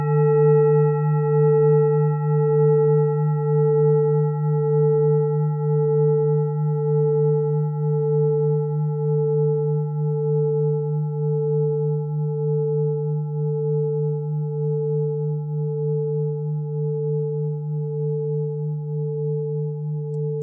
Tibet Klangschale Nr.22, Planetentonschale: Eros-Ton
Klangschalen-Gewicht: 1560g
Klangschalen-Durchmesser: 23,3cm
(Ermittelt mit dem Filzklöppel)
Die Klangschale hat bei 150.14 Hz einen Teilton mit einer
Die Klangschale hat bei 153.32 Hz einen Teilton mit einer
Signalintensität von 100 (stärkstes Signal = 100) :
Die Klangschale hat bei 441.4 Hz einen Teilton mit einer
Die Klangschale hat bei 442.38 Hz einen Teilton mit einer
Signalintensität von 28.45 (stärkstes Signal = 100) :
klangschale-tibet-22.wav